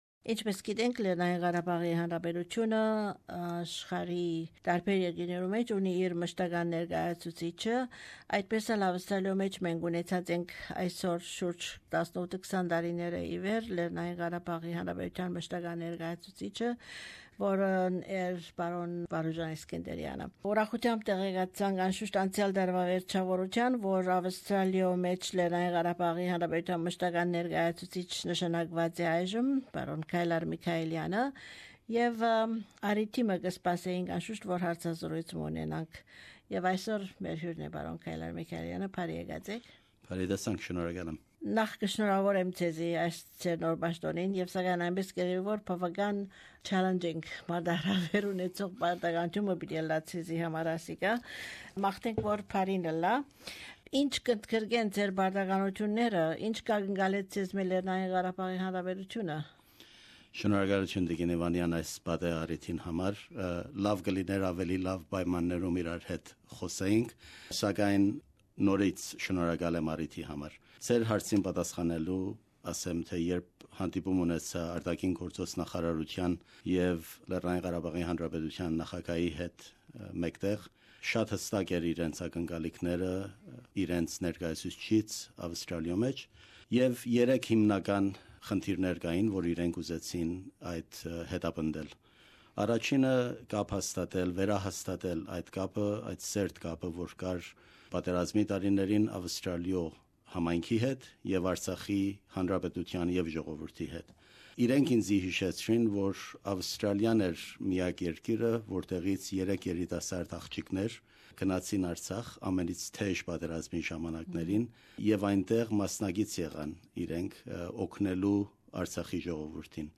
Հարցազրոյց Պրն Քայլար Միքայէլեանի հետ